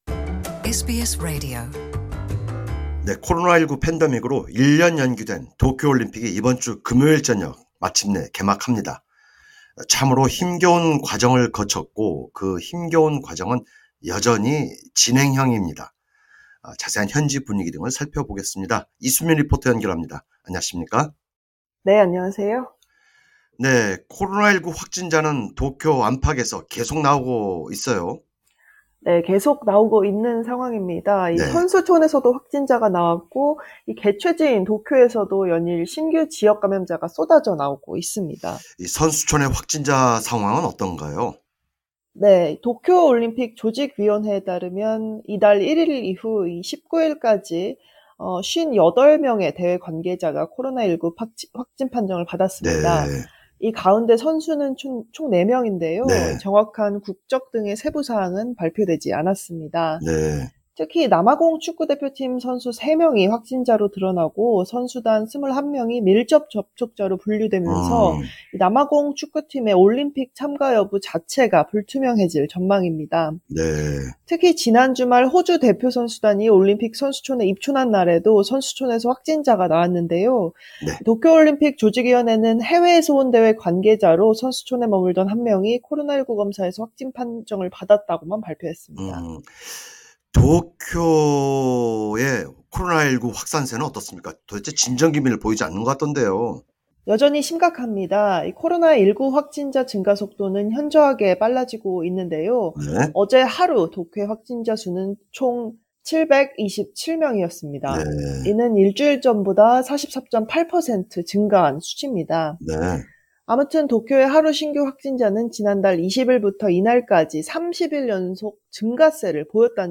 진행자: 도쿄의 코로나19 확산세는 어떻습니까.